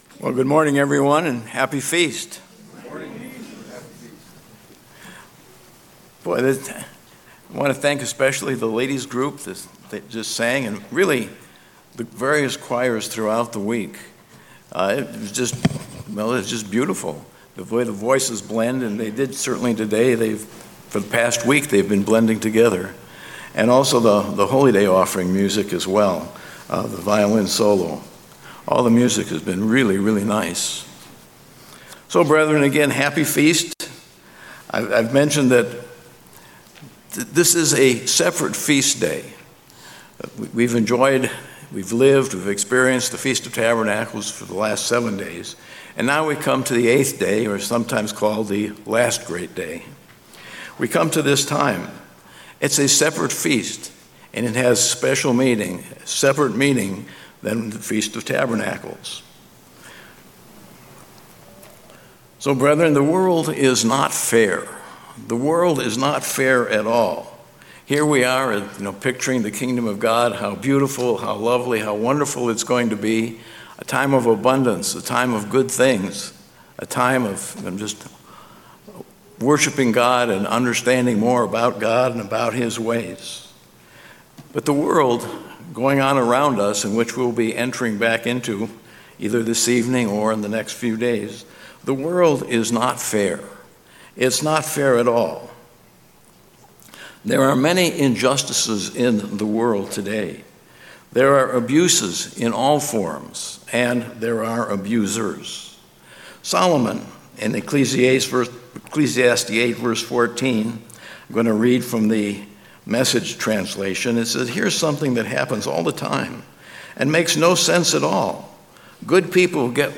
Feast of Tabernacles Transcript This transcript was generated by AI and may contain errors.